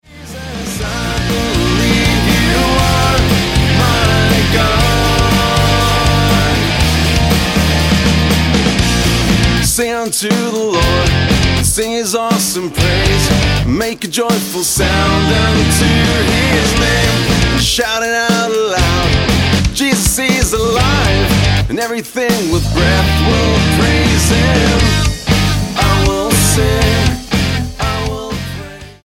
Style: Rock Approach: Praise & Worship